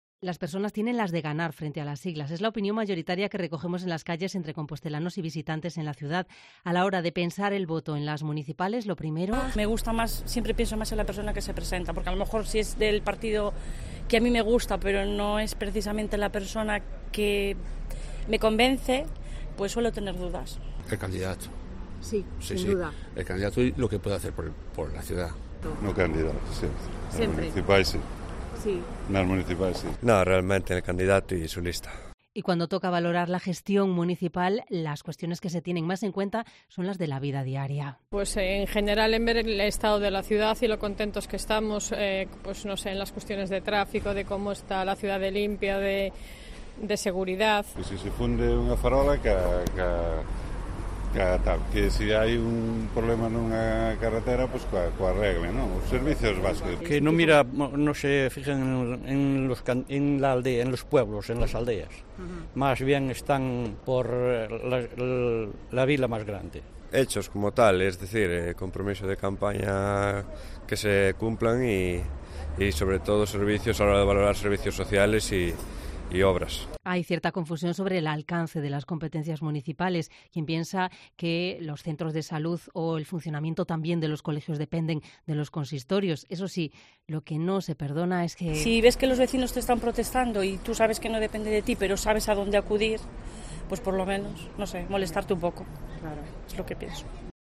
¿Candidato o partido? Salimos a la calle a preguntar